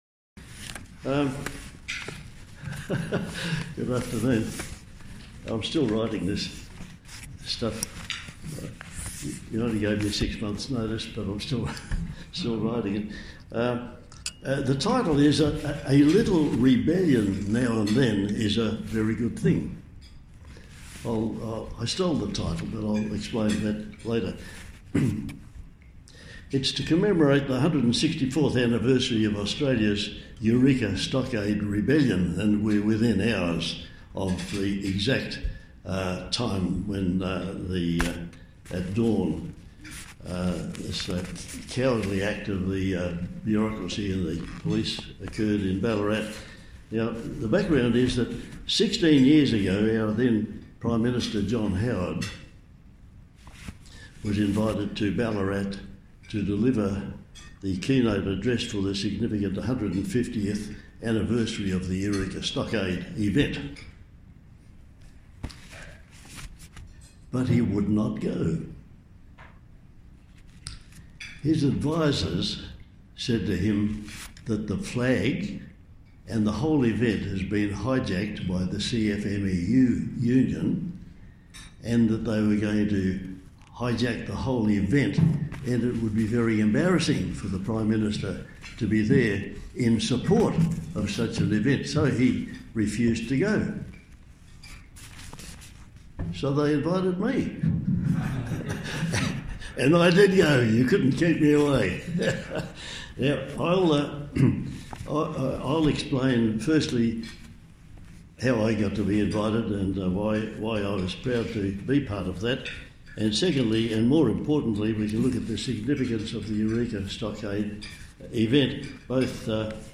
Rotary Club of Perth Westin Hotel – Perth